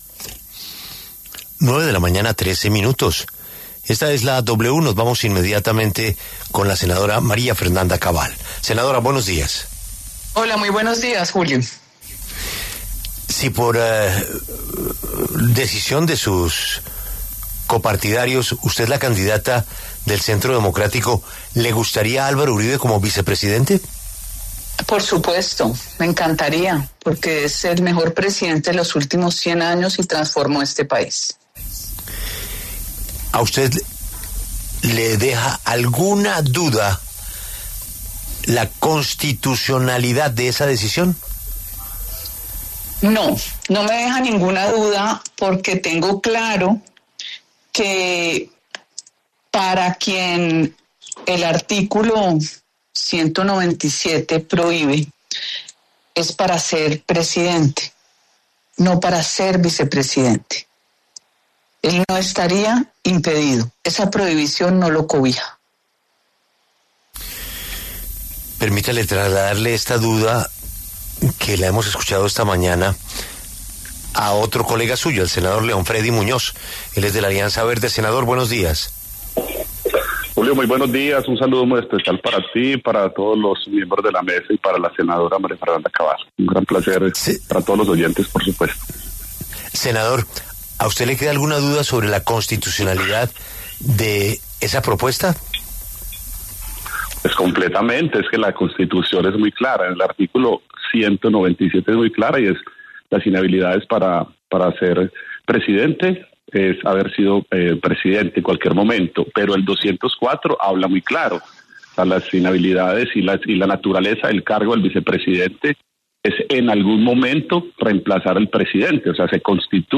María Fernanda Cabal, del Centro Democrático, y León Freddy Muñoz, de la Alianza Verde, debatieron en los micrófonos de La W sobre la posibilidad de que Álvaro Uribe sea formula vicepresidencial en la contienda de 2026.